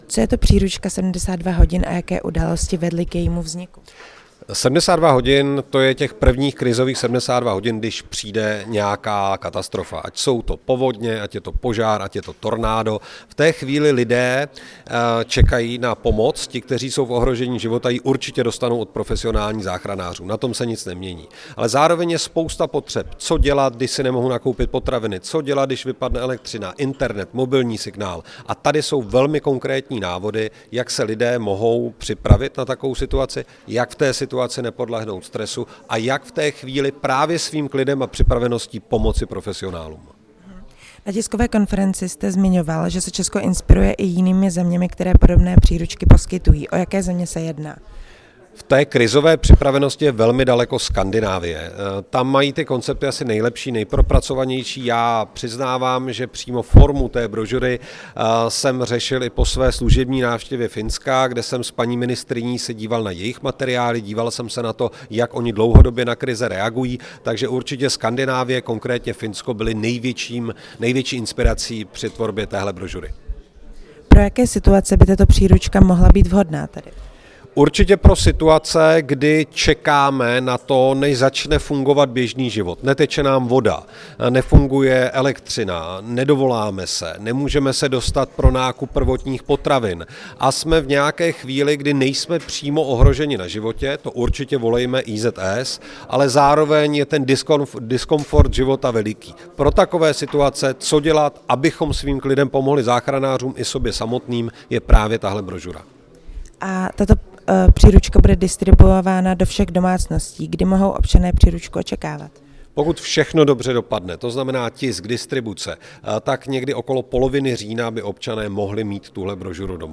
Rozhovor s ministrem vnitra Vítem Rakušanem